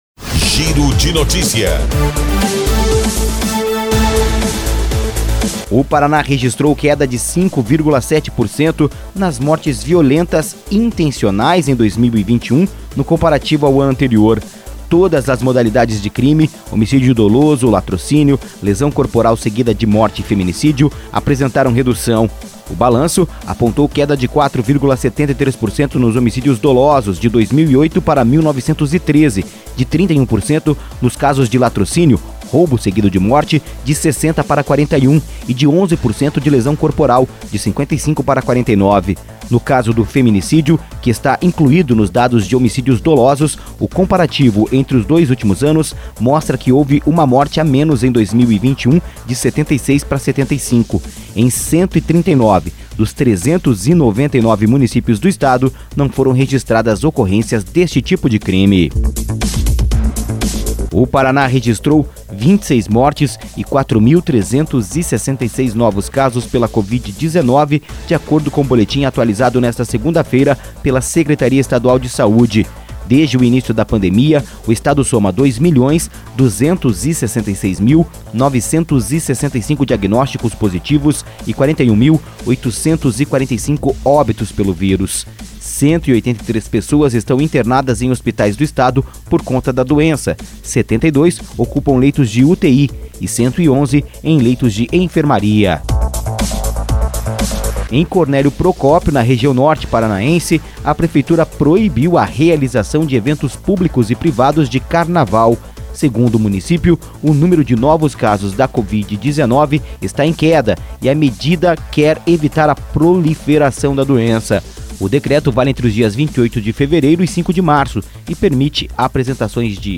Giro de Notícias – Manhã